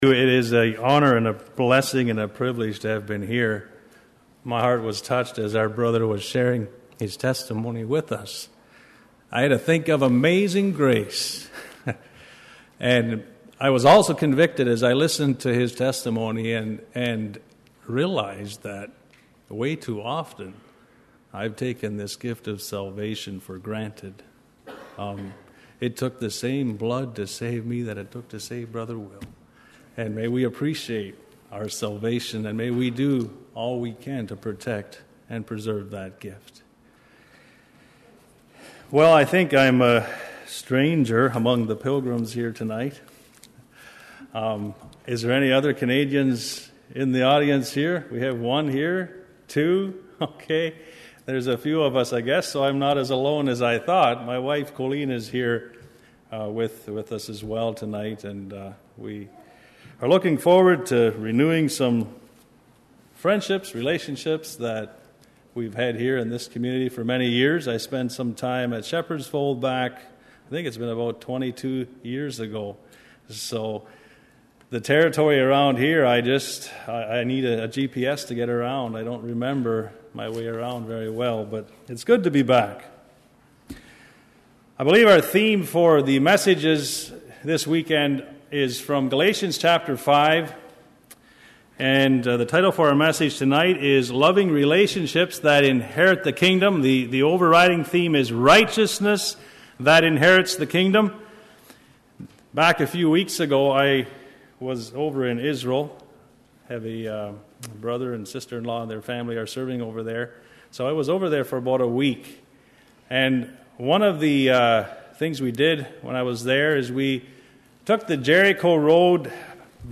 A sermon on relationships. Addresses many practical areas where we as humans struggle in relationships and what we can do to change. An illustration with a sailboat and likening different parts of the boat to different components in our Christian life.